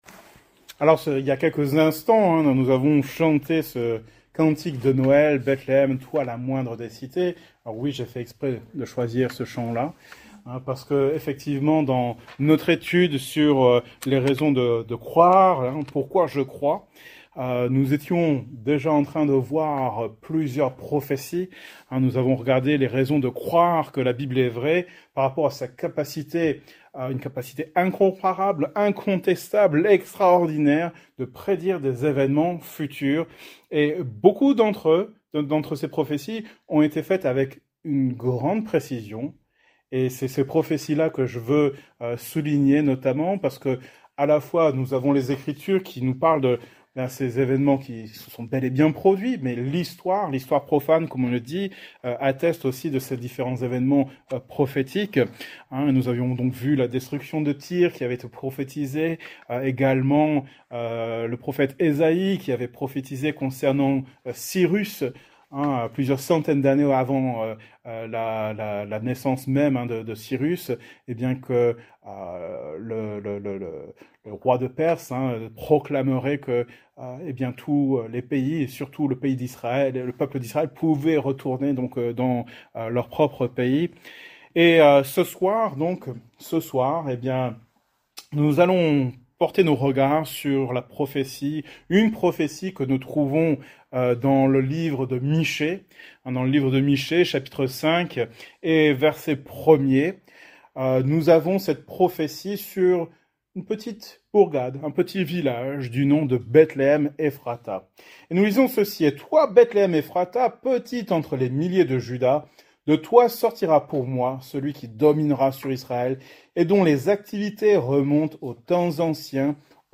Thème: Prophéties Genre: Etude Biblique